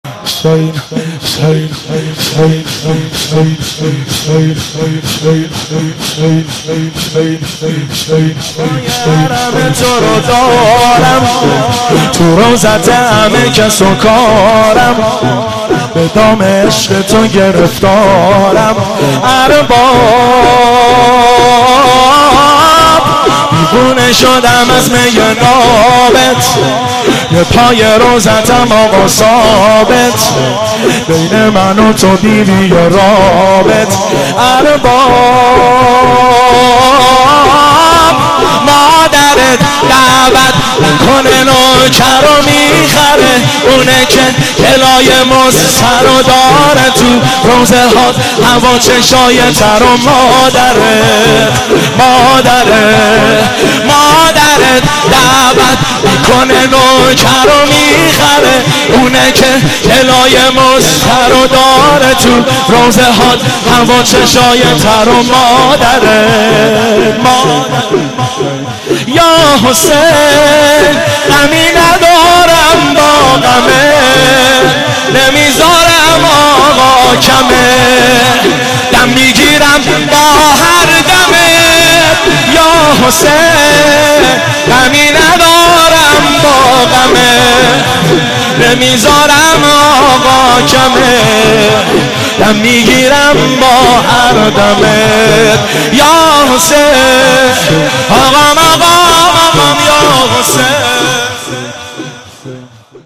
مناسبت : شب بیست و دوم رمضان
قالب : شور